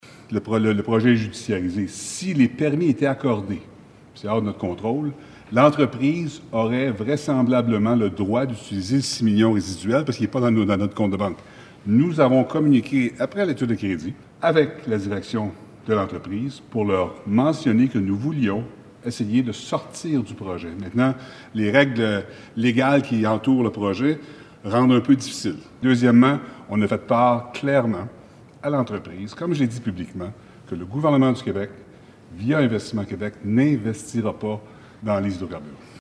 Le sujet a rebondi de nouveau mercredi à l’Assemblée nationale alors que, la députée de Gaspé, Méganne Perry Mélançon a demandé au ministre de l’Économie, Pierre Fitzgibon, comment il allait faire pour récupérer l’argent investi dans Gaspé Énergies.
Pierre Fitzgibbon confirme que dans le cas où le tribunal donnerait raison à l’entreprise et accorde le permis, elle pourrait effectivement dépenser les 6 millions restants.